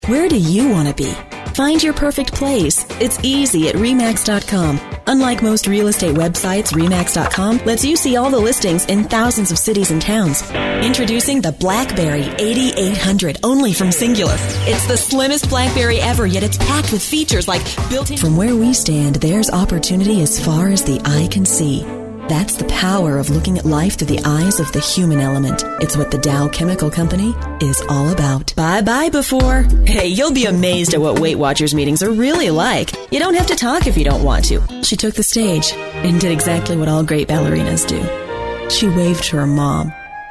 American English Voice Over artist
Sprechprobe: Werbung (Muttersprache):